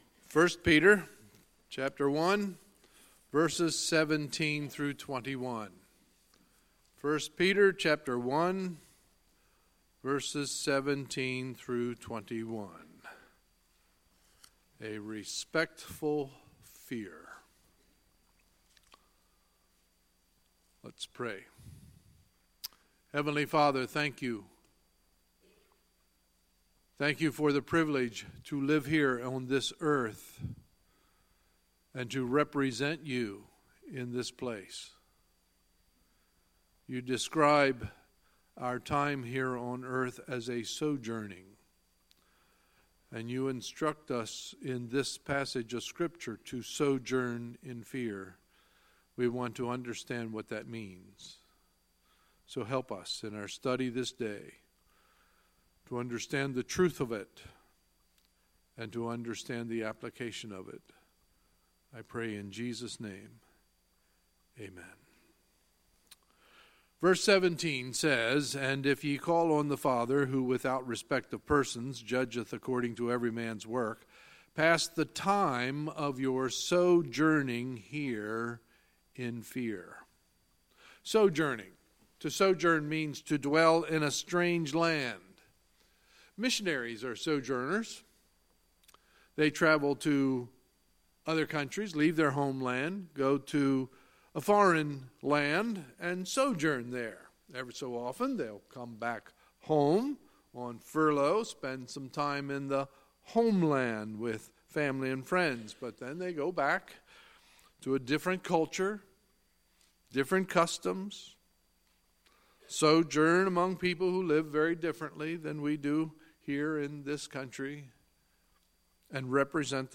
Sunday, March 11, 2018 – Sunday Morning Service